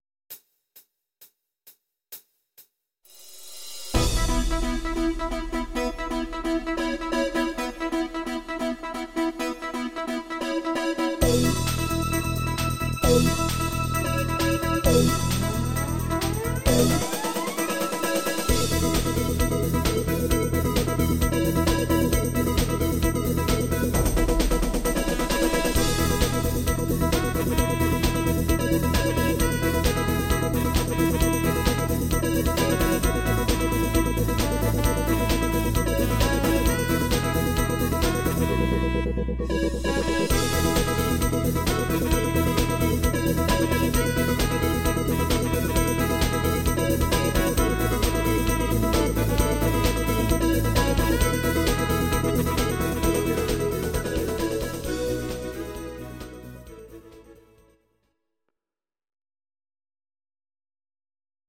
C#
Audio Recordings based on Midi-files
Pop, 1990s